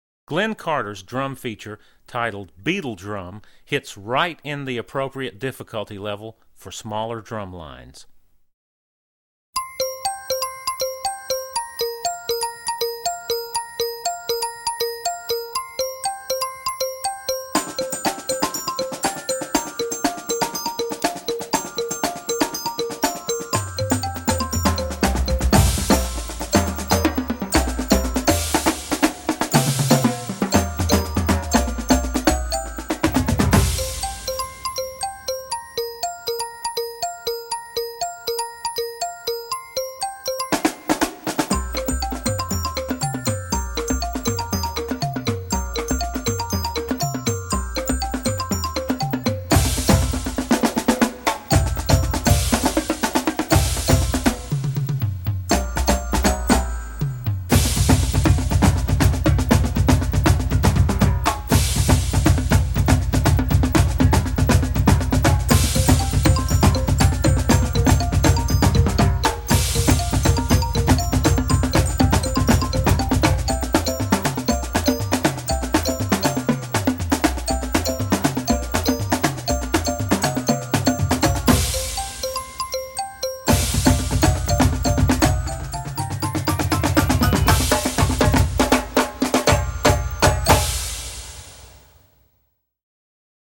Voicing: Percussion Feature